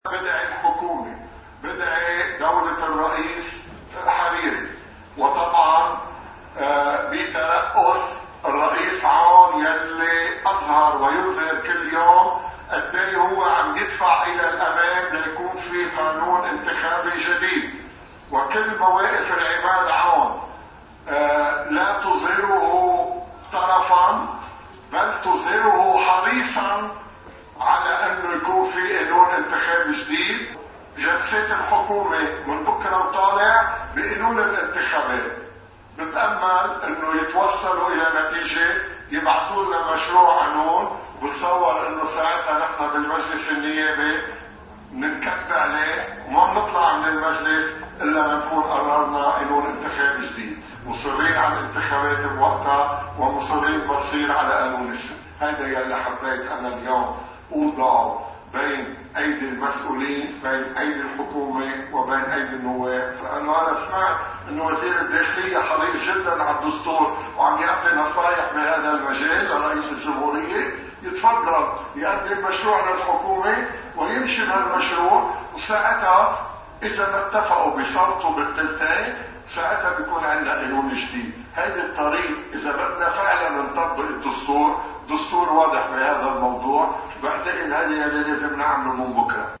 مقتطف من حديث النائب عن كتلة القوات اللبنانية جورج عدوان من مجلس النواب بخصوص قانون الإنتخابات: